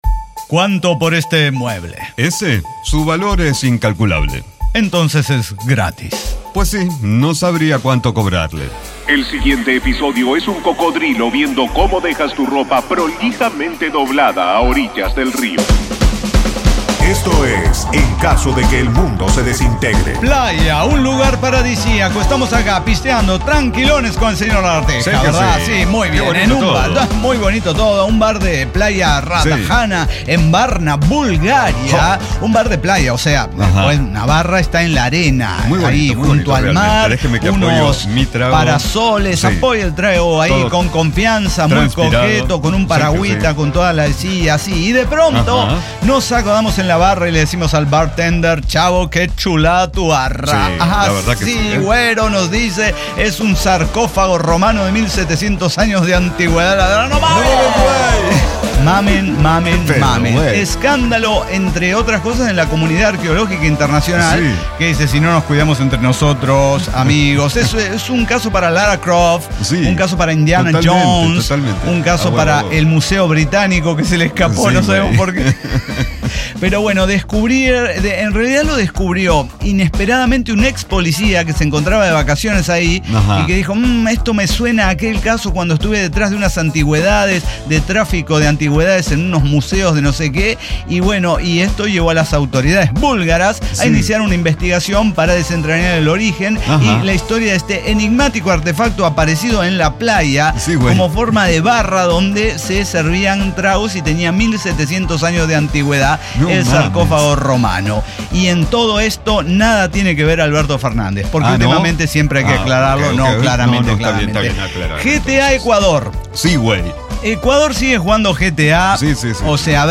El Cyber Talk Show